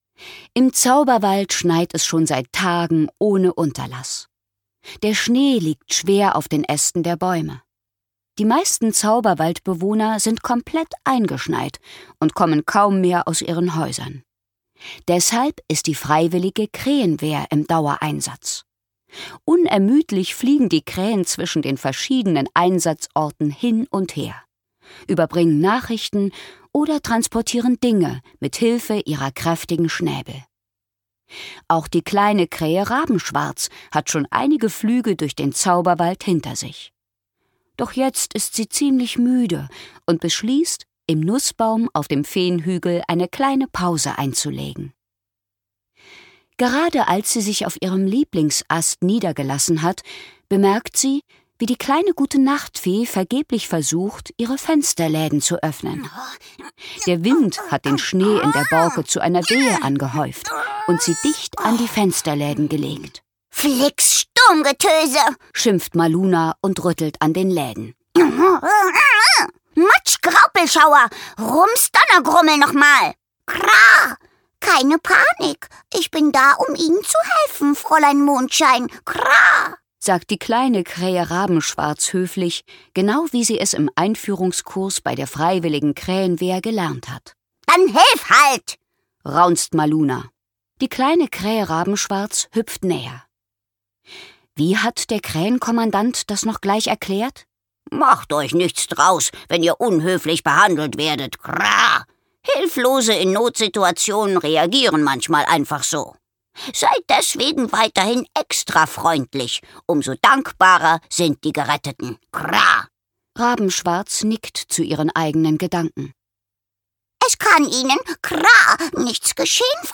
Hörbuch: Maluna Mondschein.
Maluna Mondschein. Adventskalenderhörbuch Andrea Schütze